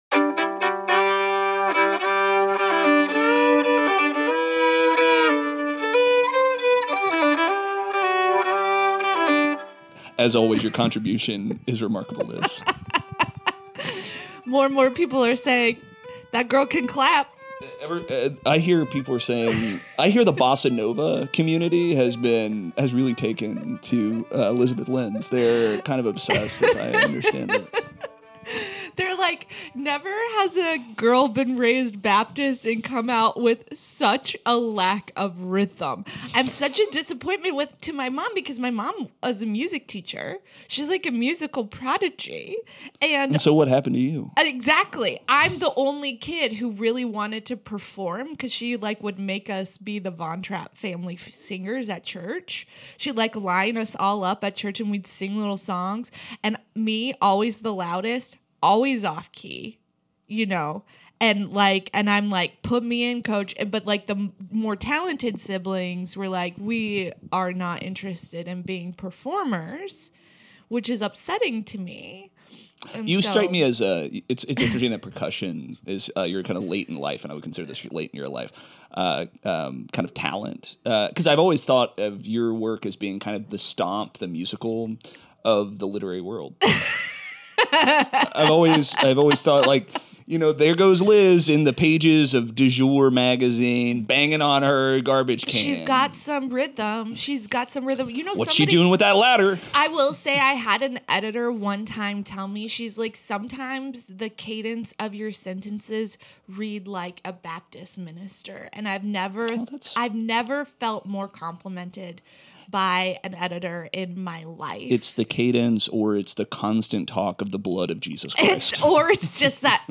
DINGUS OF THE WEEK is a weekly comedy news podcast